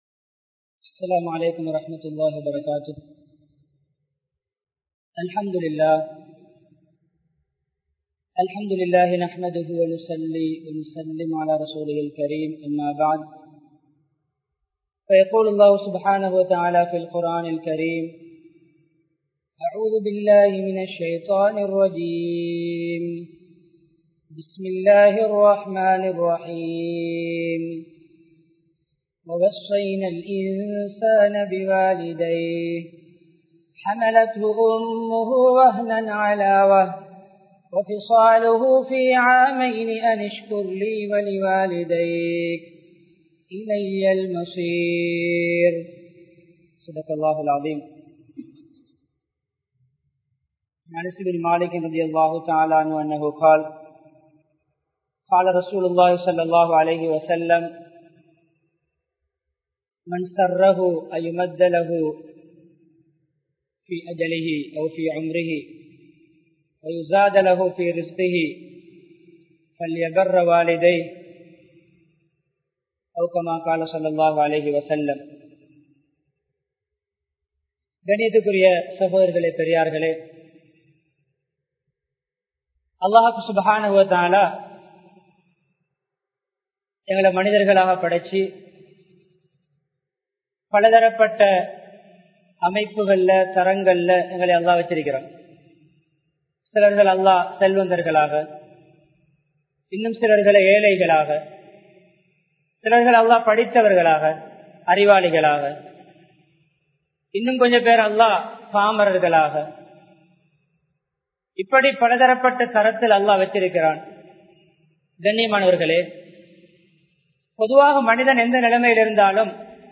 பெற்றோர்களின் பெறுமதி | Audio Bayans | All Ceylon Muslim Youth Community | Addalaichenai
Thalduwa, Town Jumua Masjidh